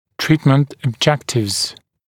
[‘triːtmənt əb’ʤektɪvz] [ɔb-][‘три:тмэнт эб’джэктивз] [об-]цели лечения